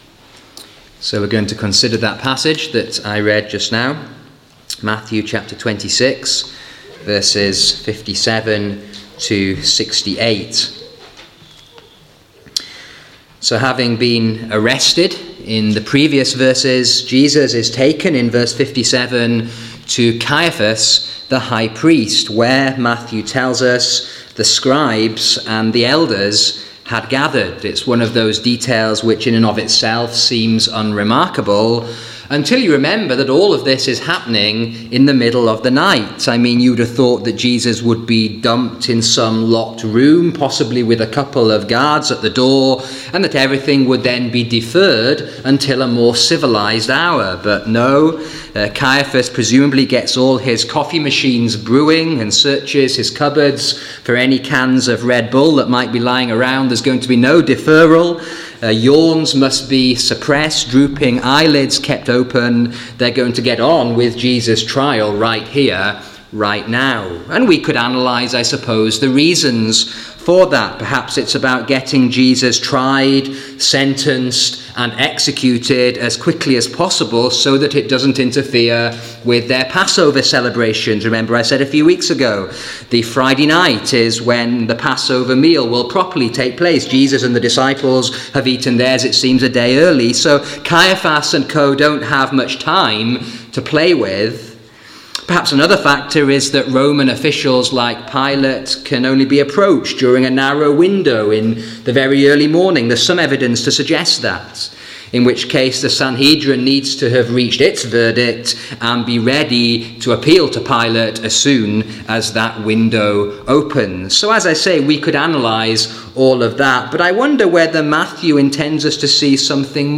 Sermons: reverse order of upload